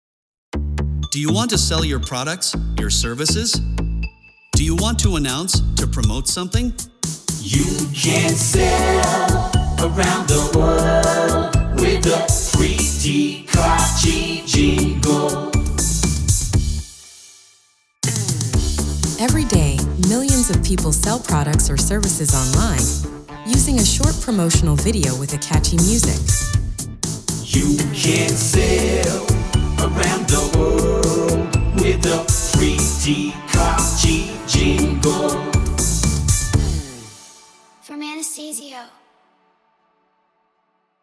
Μουσική για διαφημιστικά (jingles)
Εμείς προσθέτουμε μουσική και επεξεργασία φωνής.